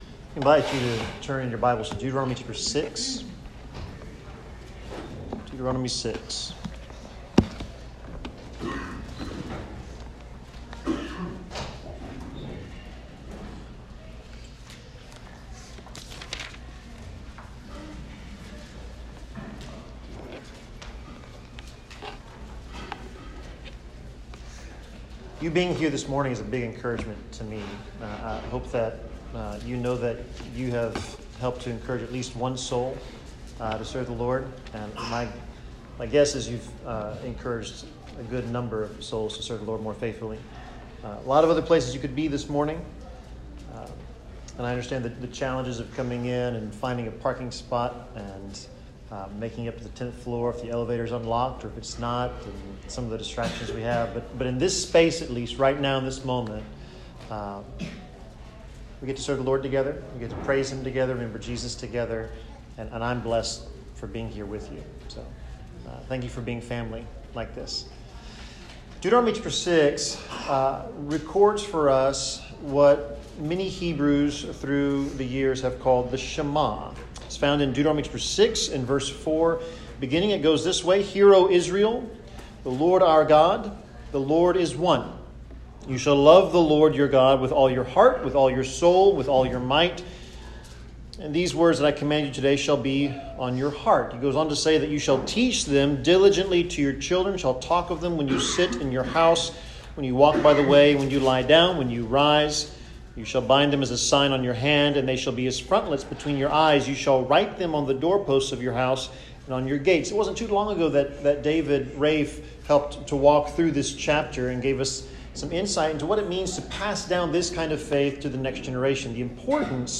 Sermon 11/27/2022: The Shema
Passage: Deuteronomy 6:4-9 Service Type: Sermon Topics